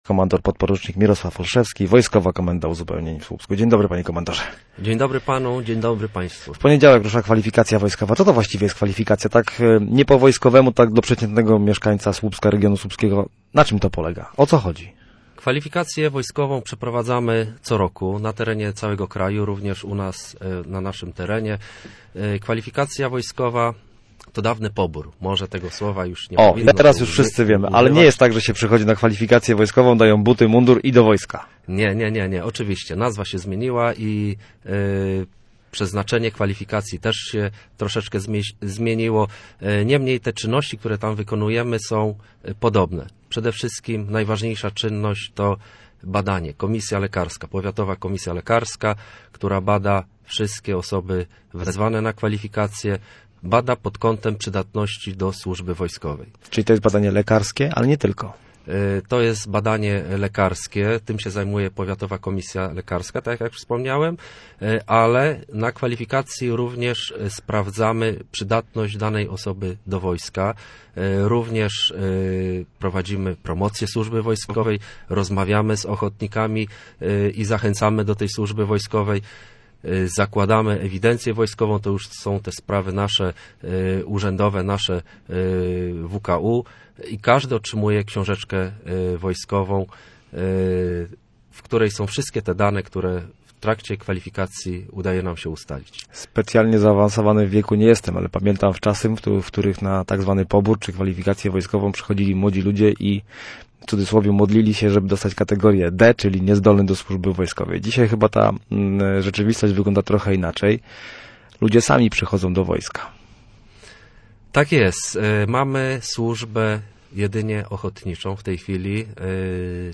Oficer był gościem miejskiego programu Radia Gdańsk Studio Słupsk 102 FM.